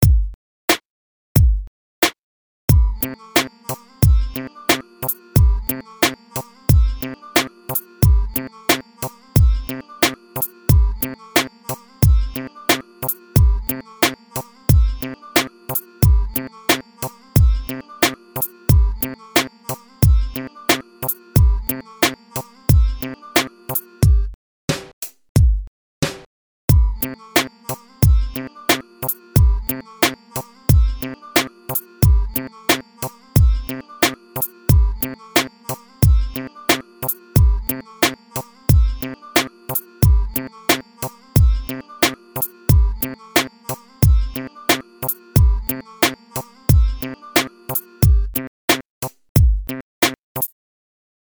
Еще один минусок...
Музыка 2005-го...Голос засемплировал (а потом замучал) из California Love...Ну забавно, вроде, вышло...На любителя West Coast образца 93-96 годов...